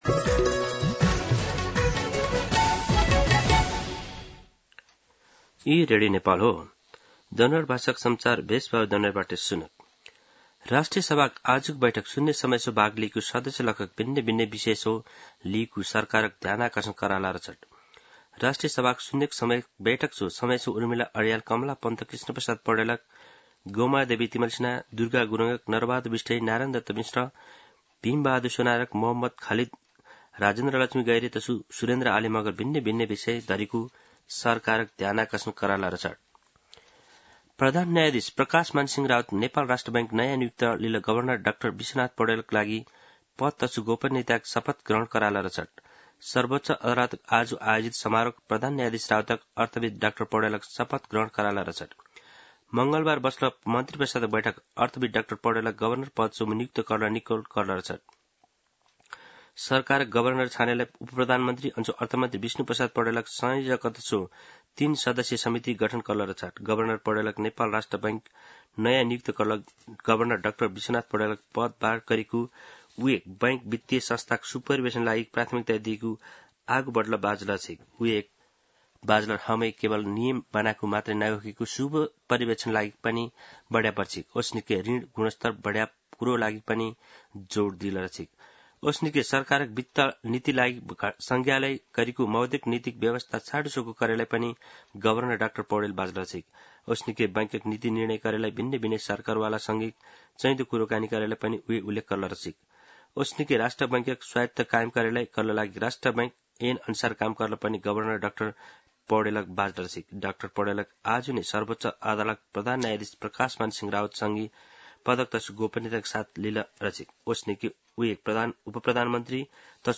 दनुवार भाषामा समाचार : ७ जेठ , २०८२
Danuwar-News-02-07.mp3